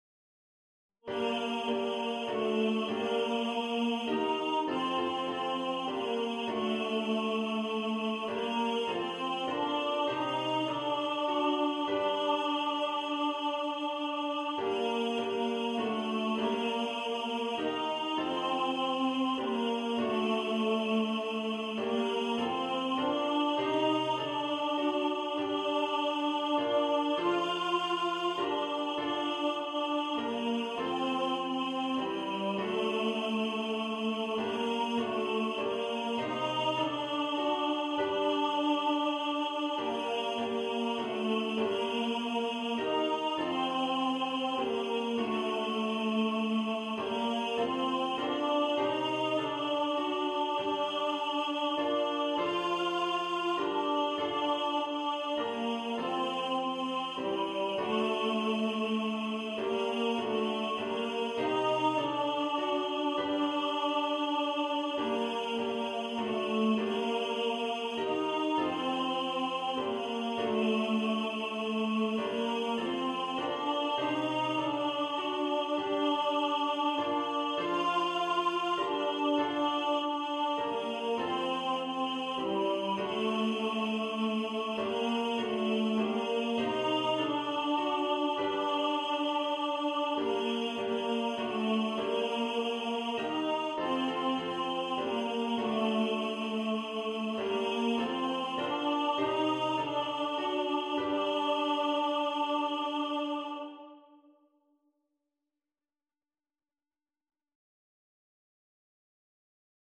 Coventry-Carol-Tenor.mp3